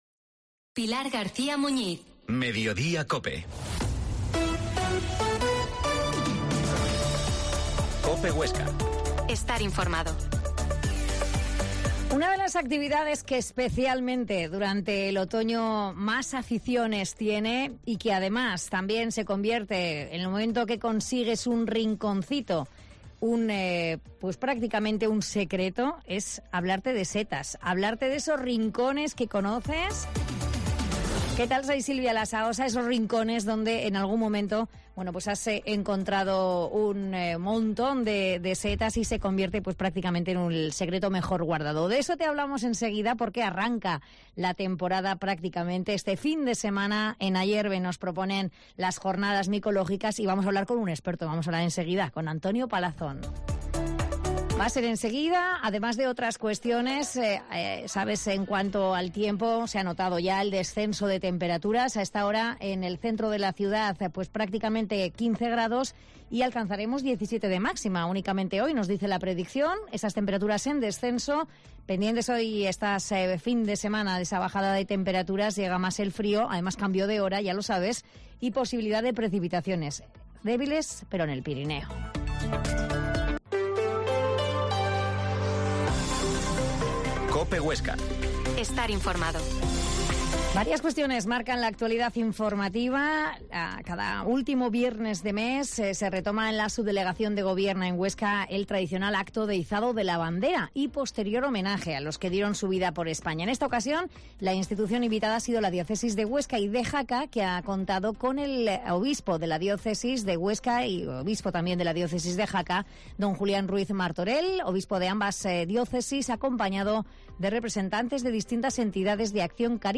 Mediodia en COPE Huesca 13.20 Entrevista a micólogo